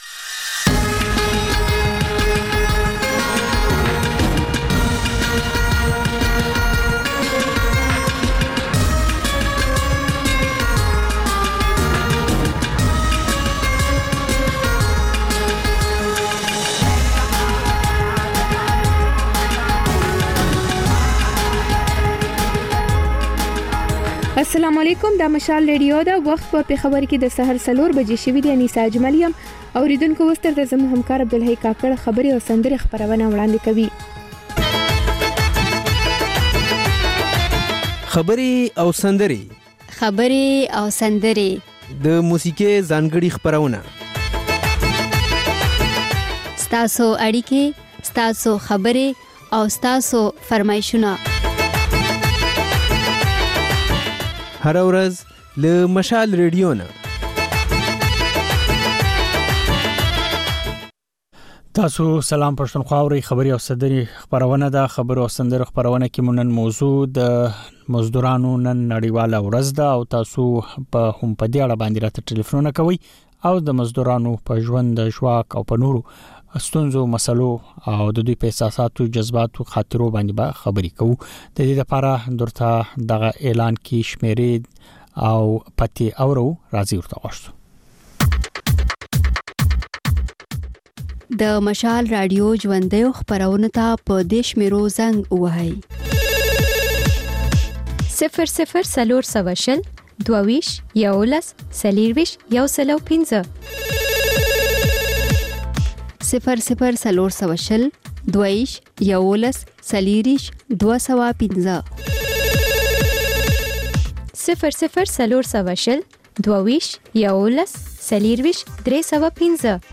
په دې خپرونه کې له اورېدونکو سره خبرې کېږي، د هغوی پیغامونه خپرېږي او د هغوی د سندرو فرمایشونه پوره کېږي. دا یو ساعته خپرونه د پېښور پر وخت سهار پر څلور او د کابل پر درې نیمو بجو تکرار خپرېږي.